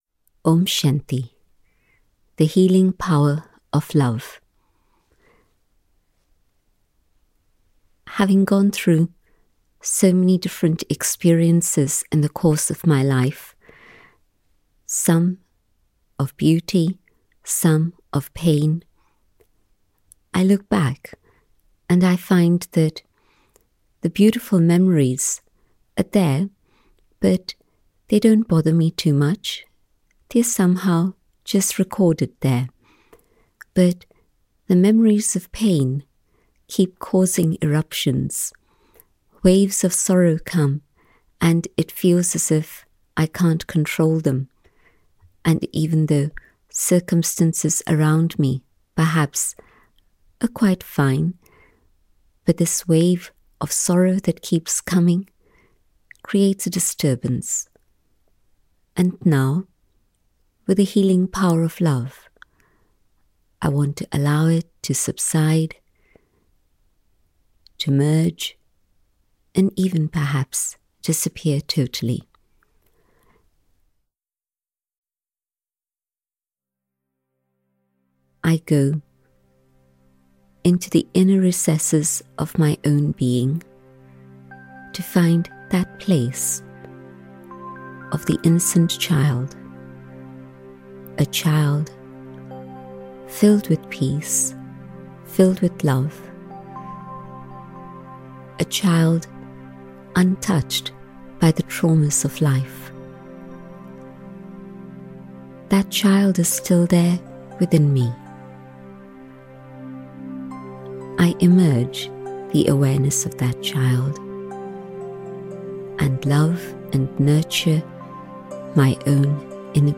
Downloadable Meditations